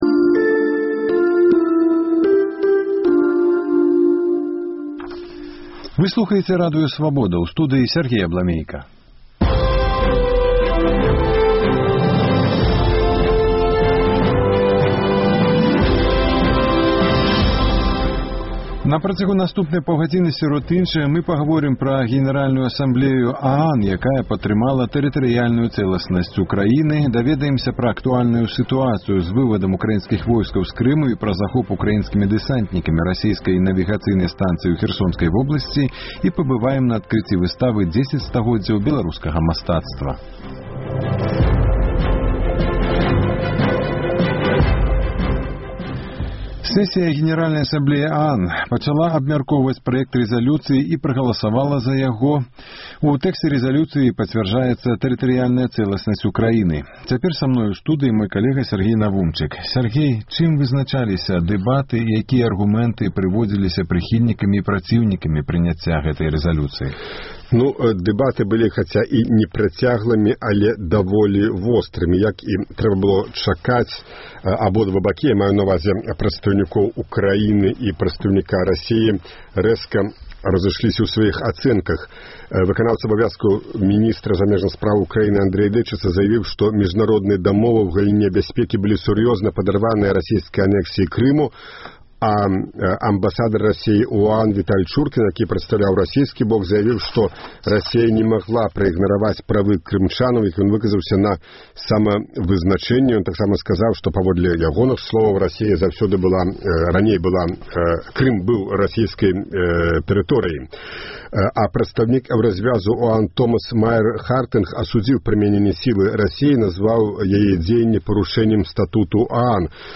Украінскія дэсантнікі ўзялі пад кантроль навігацыйную станцыю «Марс-75» у Генічаску Херсонскай вобласьці, якая з часоў распаду СССР належыць Чарнаморскаму флёту Расеі. Рэпартаж з Украіны.